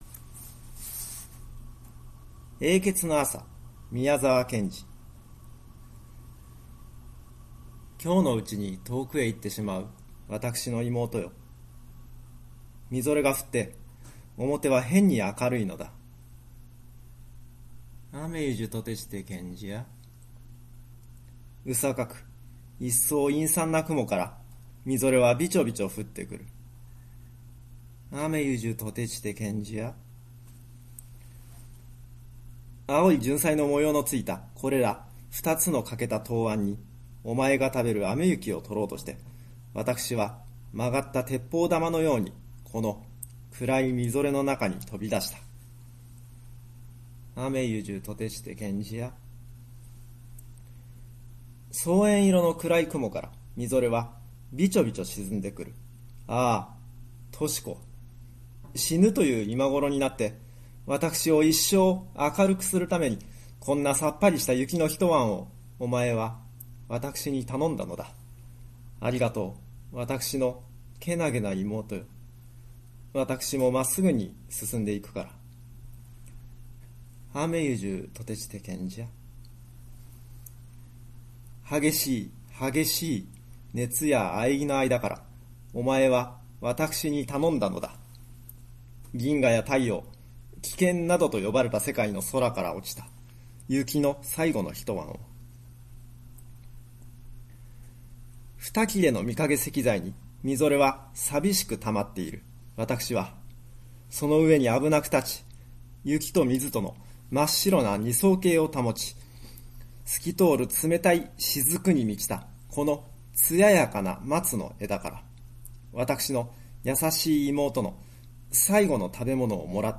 （永訣の朝　朗読）